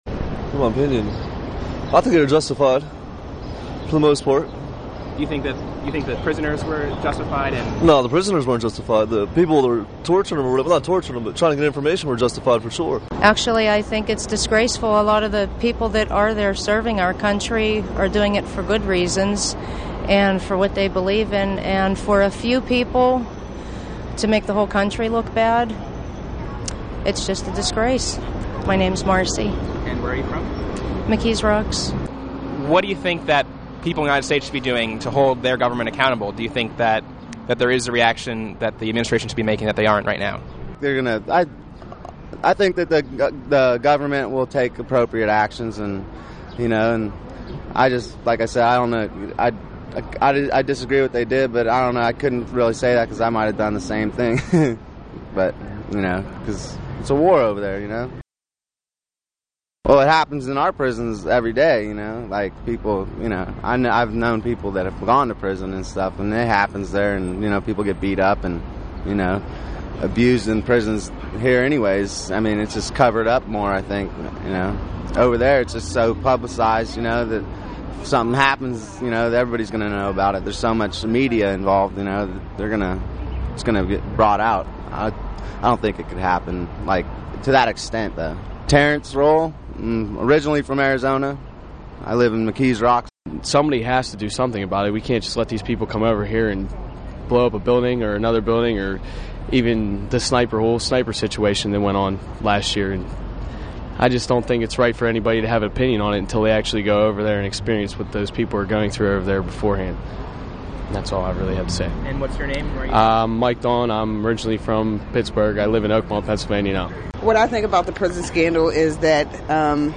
Street Interviews on Abu Ghraib Scandal
We went to Downtown Pittsburgh to interview ordinary Pittsburghers on their opinions about the Abu Ghraib prison scandal.
street_interviews.mp3